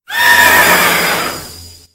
infinitefusion-e18 / Audio / SE / Cries / SPECTRIER.ogg